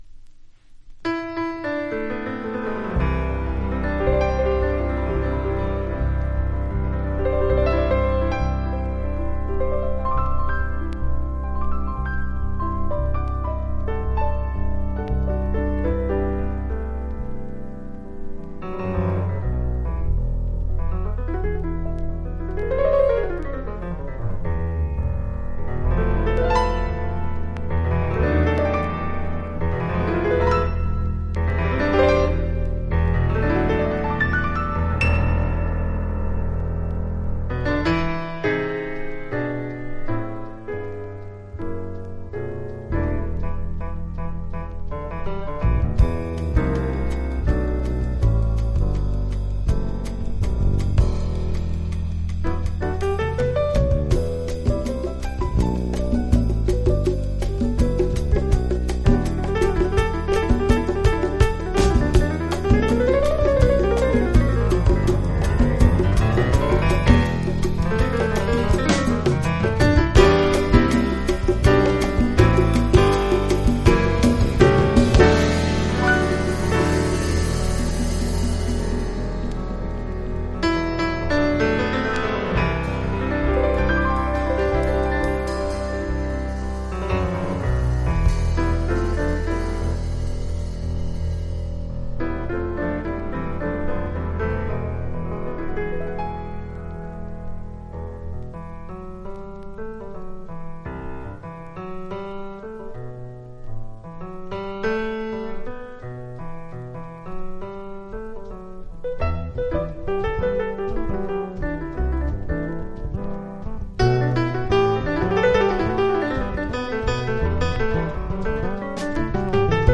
（プレスにより少しチリ、プチ音ある曲あり）※曲名をクリックすると試聴できます。